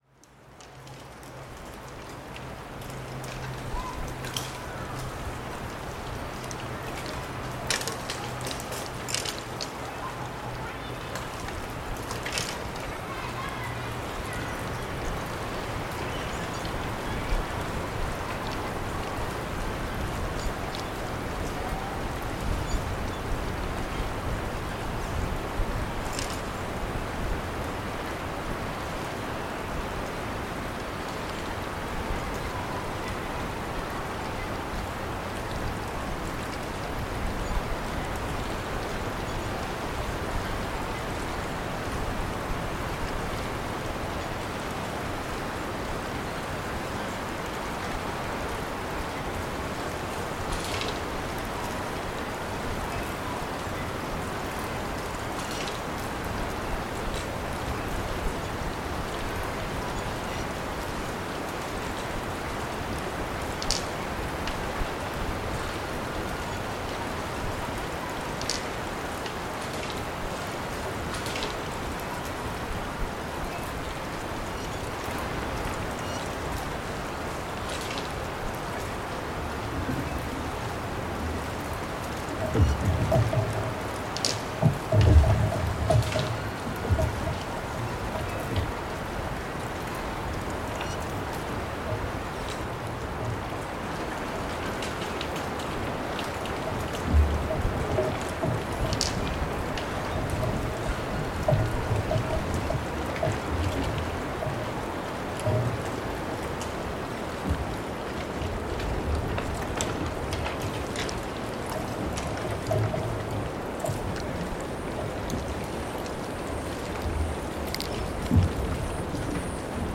Composition for Radio aired on 17.10.2020